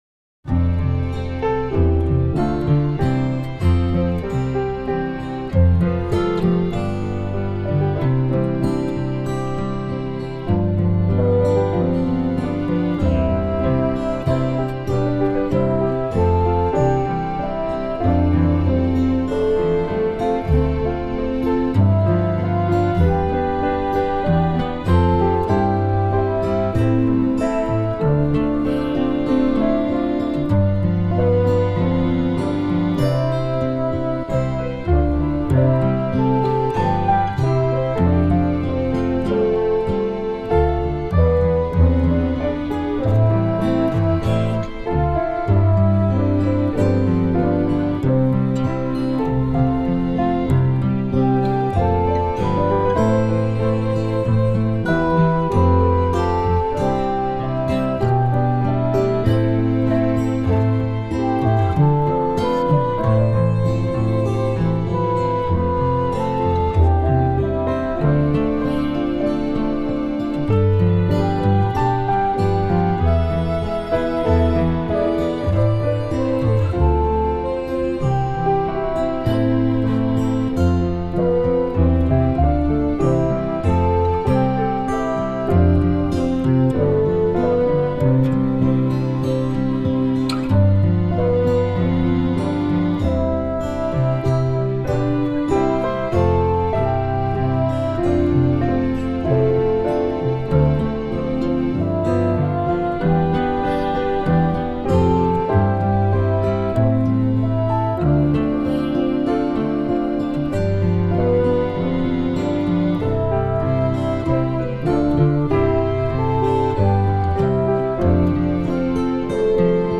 You know the tune but I did a backing in any case.